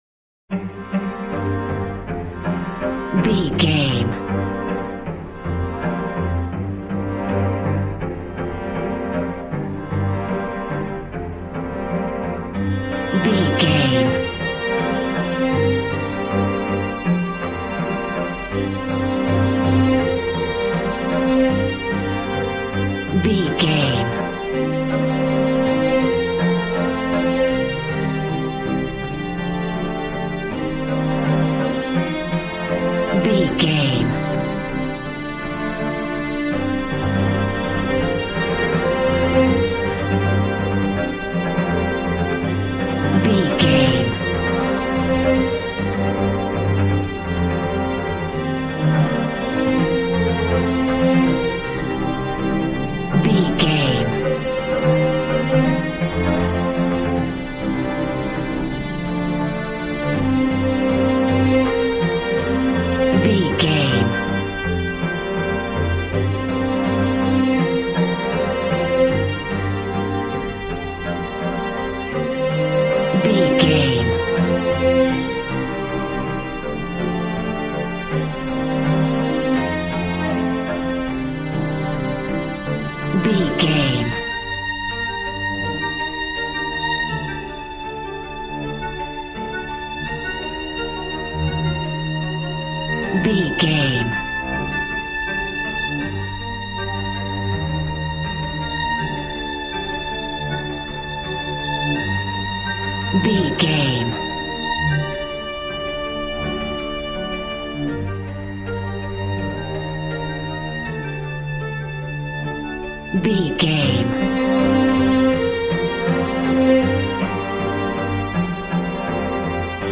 Beautiful stunning solo string arrangements.
Regal and romantic, a classy piece of classical music.
Aeolian/Minor
regal
strings
brass